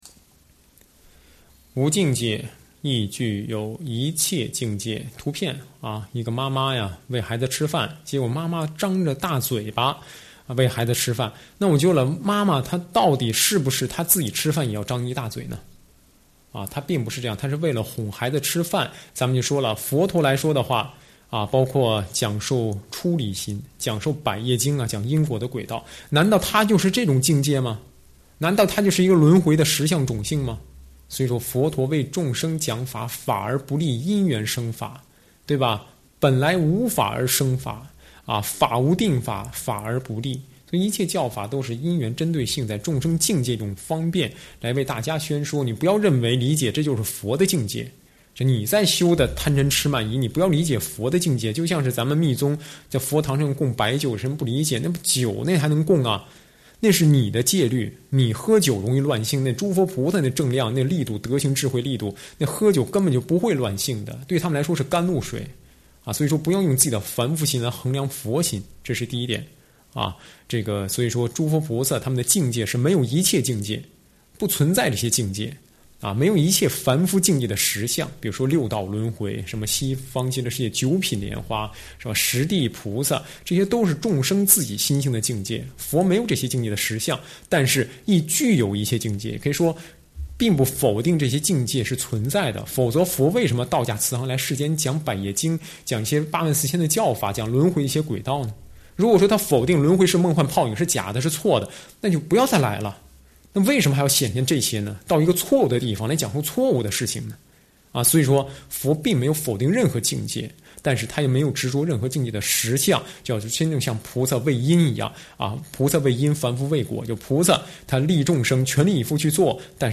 随笔开示
上师语音开示